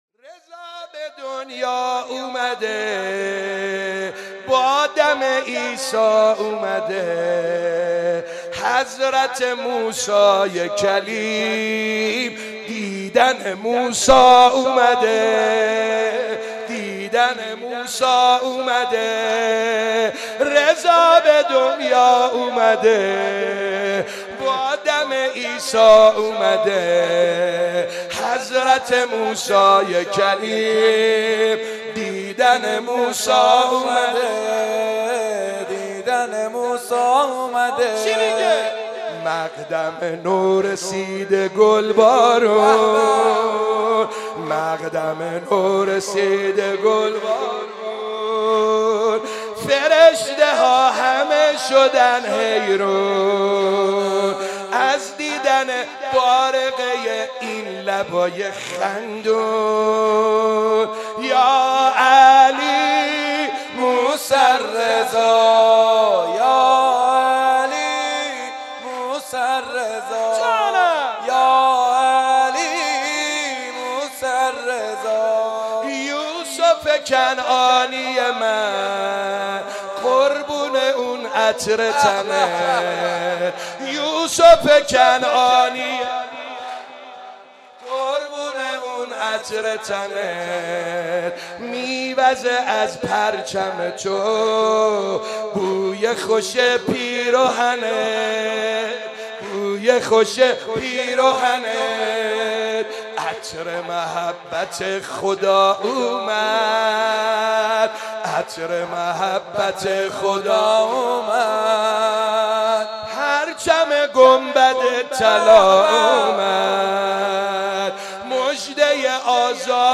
در مهدییه امام حسن(ع)برگزار شد
مولودی